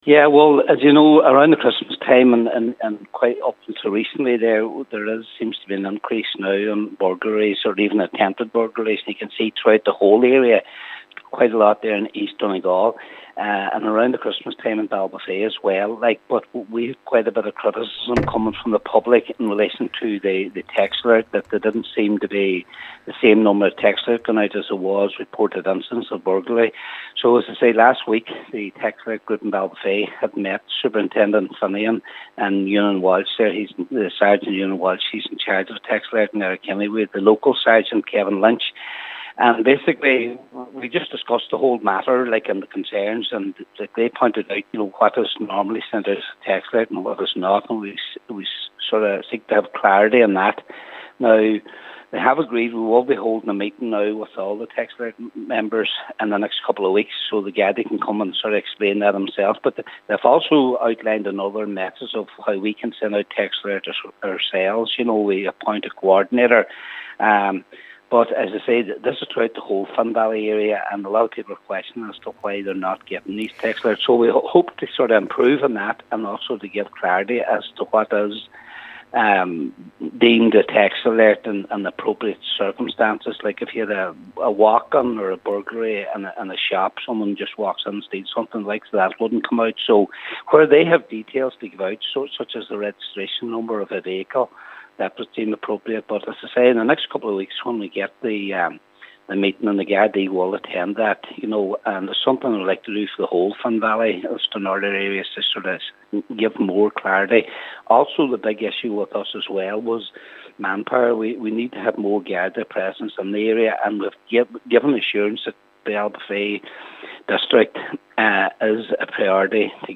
Cllr Patrick McGowan says it’s important for the community to get the answers they seek: